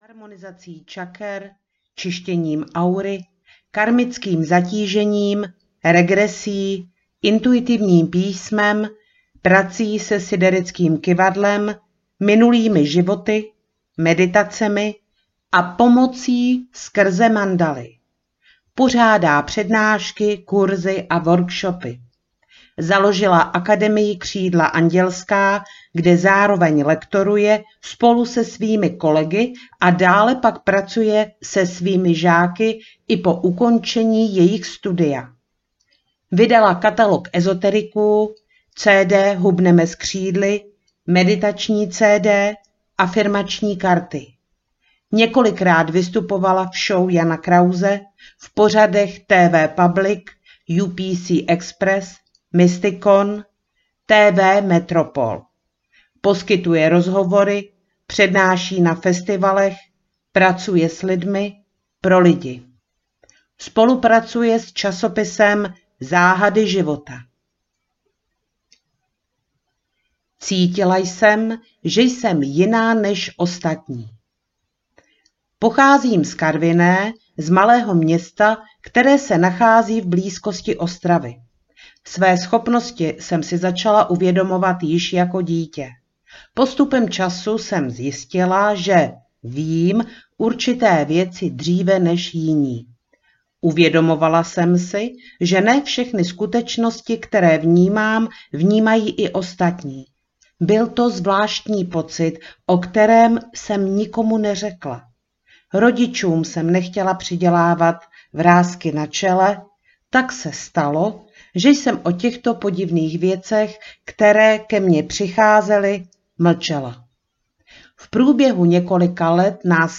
Příběhy léčitelky audiokniha
Ukázka z knihy